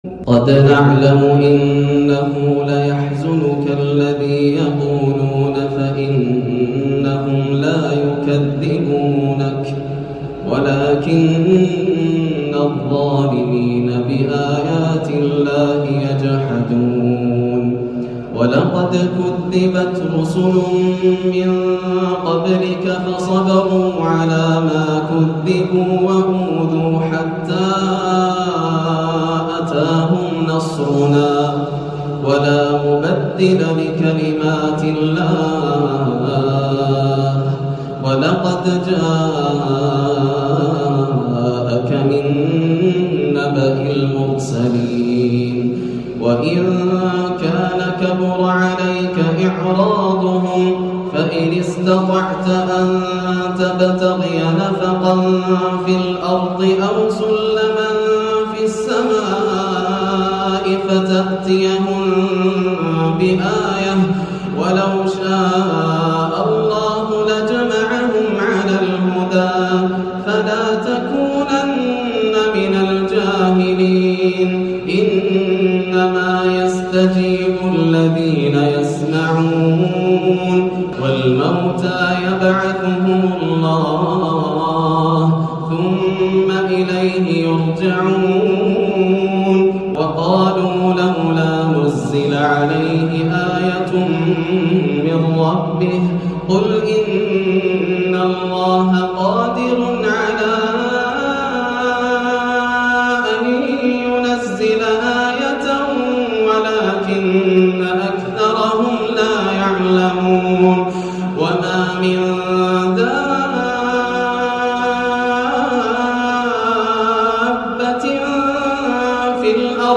تلاوة لا توصف لآيات عظمة الله تعالى وقدرته - قمة الخشوع واستشعار الآيات من شيخنا الغالي > الروائع > رمضان 1430هـ > التراويح - تلاوات ياسر الدوسري